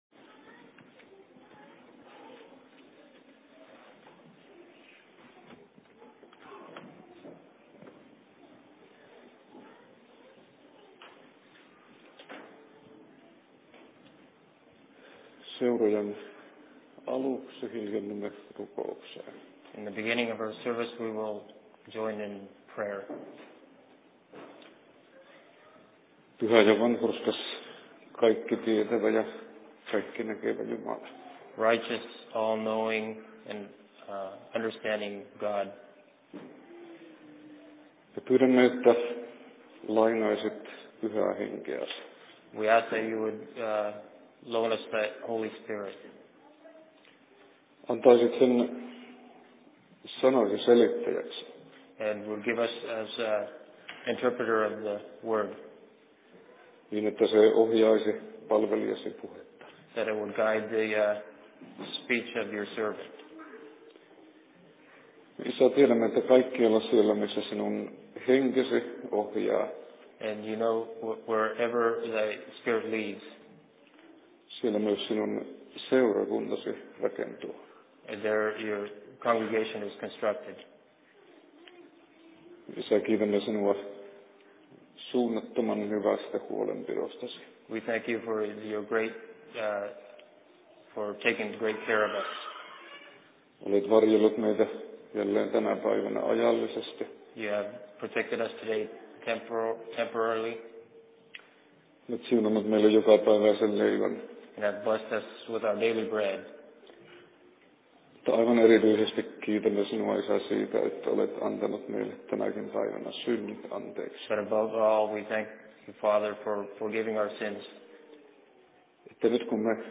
Fi En Sermon in Seattle 07.07.2009
Paikka: LLC Seattle
Simultaanitulkattu